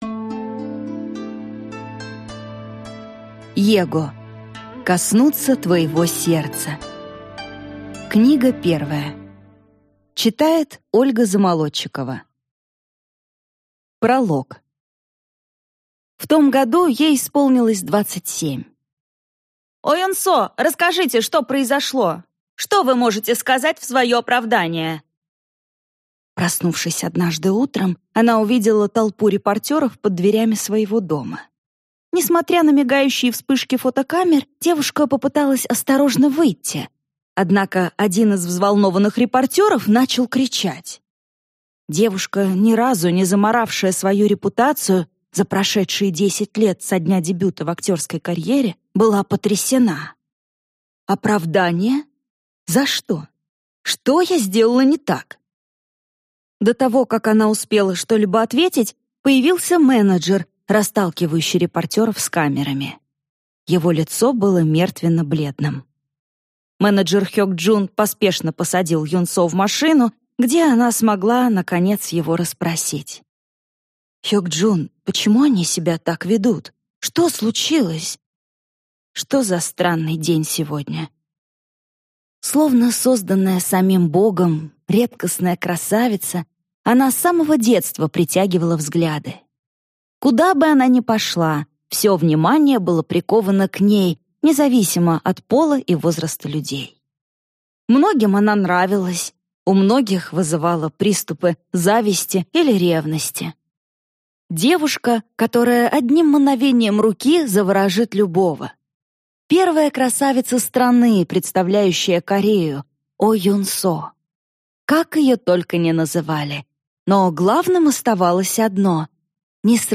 Аудиокнига Коснуться твоего сердца. Книга 1 | Библиотека аудиокниг